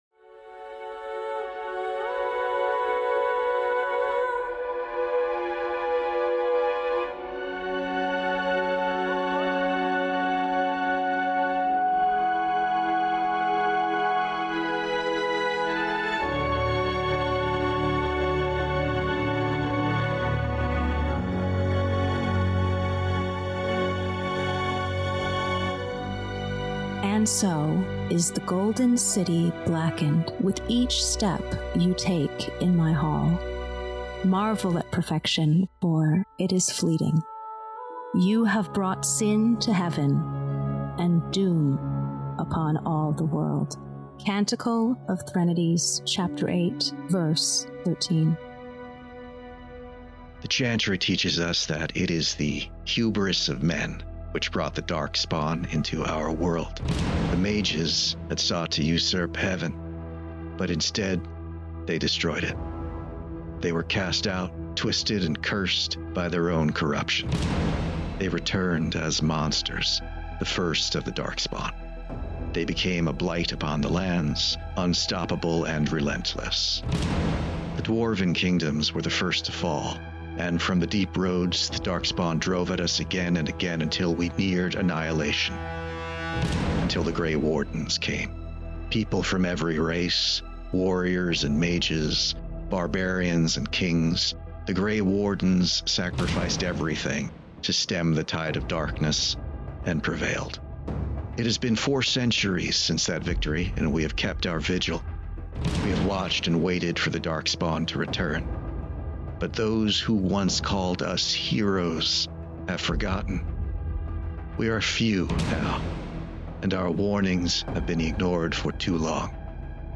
PodCast Party: An RPG Podcast